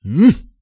XS嘲弄3.wav
XS嘲弄3.wav 0:00.00 0:00.62 XS嘲弄3.wav WAV · 54 KB · 單聲道 (1ch) 下载文件 本站所有音效均采用 CC0 授权 ，可免费用于商业与个人项目，无需署名。
人声采集素材